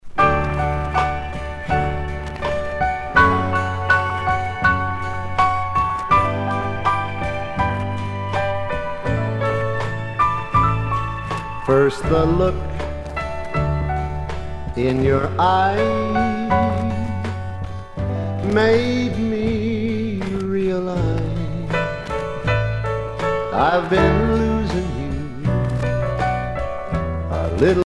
danse : slow